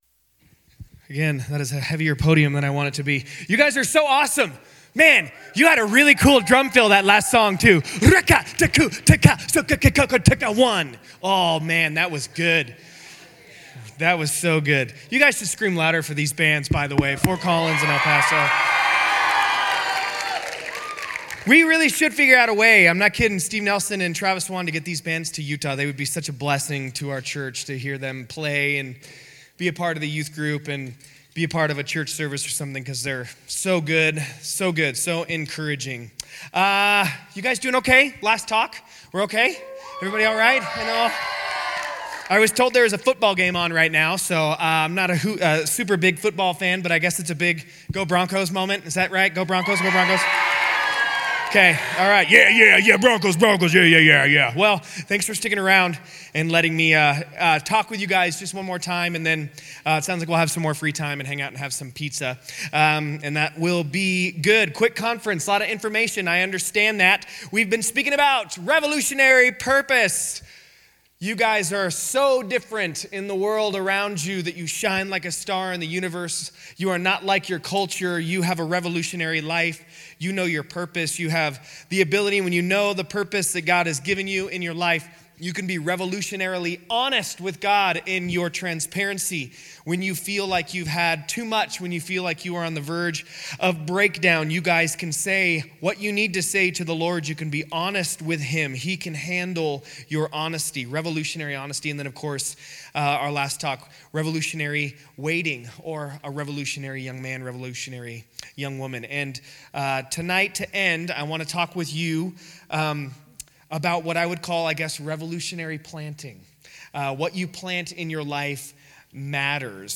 Winter Teen Conference - Teaching 4 - Summitview Church